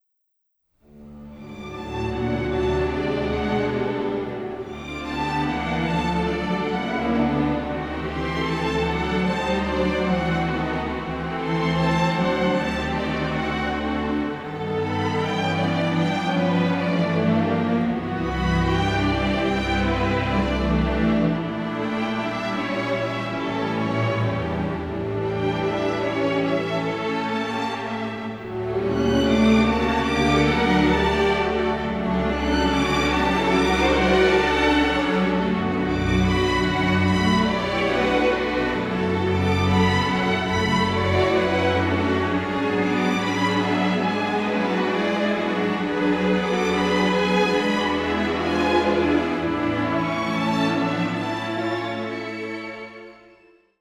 recorded at Abbey Road Studios